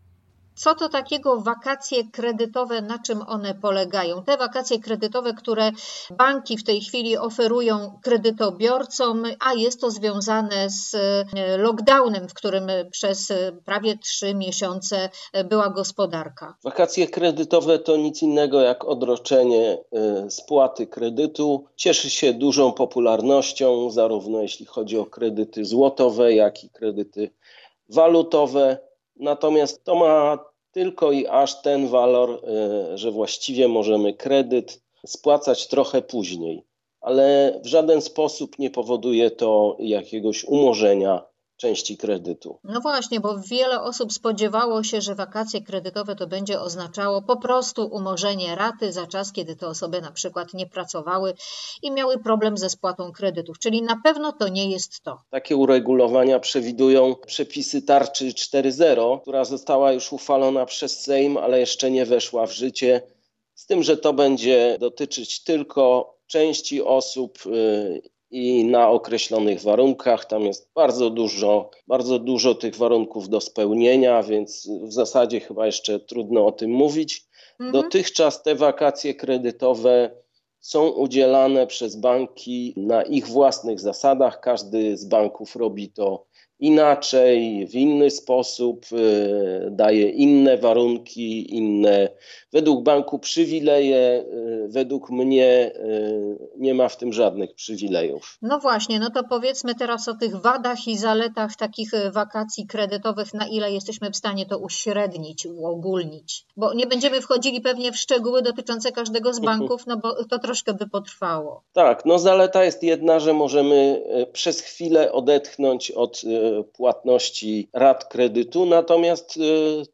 O tym rozmawialiśmy w audycji Dobrze Mieszkaj.